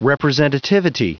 Prononciation du mot representativity en anglais (fichier audio)
representativity.wav